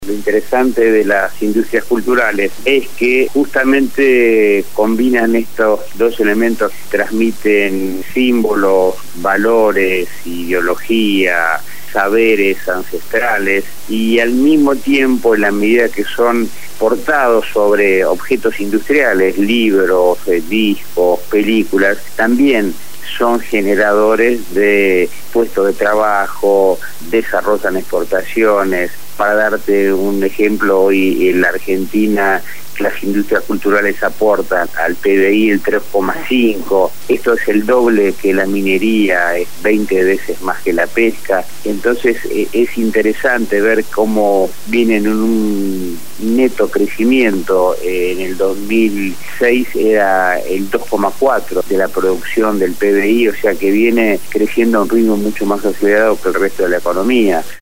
Lo afirmó Rodolfo Hamawi , Director Nacional de Políticas Culturales de la Secretaría de Cultura de la Nación, un día después de haber participado en la inauguración de una nueva edición de la Feria del Libro en el predio ferial de Palermo, en la Ciudad de Buenos Aires. En diálogo con el programa «Punto de Partida» de Radio Gráfica FM 89.3